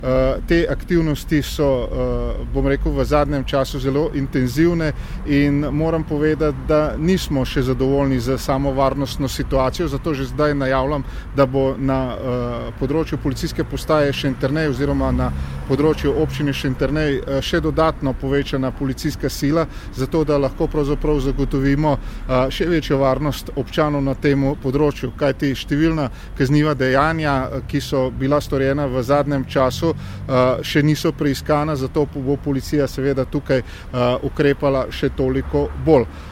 Notranji minister Boštjan Poklukar o tem, da bo policija okrepila svoje aktivnosti na območju PP Šentjernej